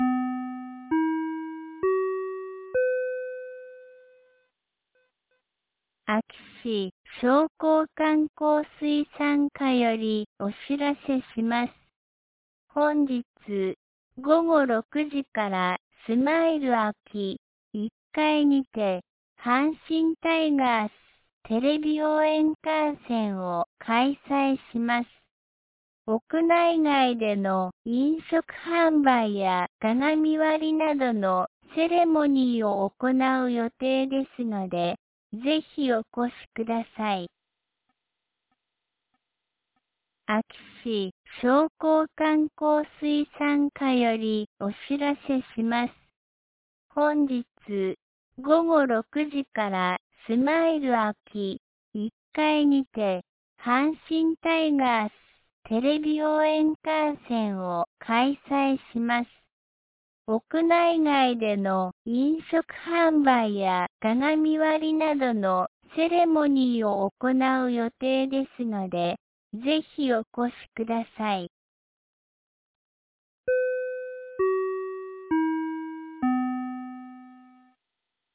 2025年09月07日 13時16分に、安芸市より全地区へ放送がありました。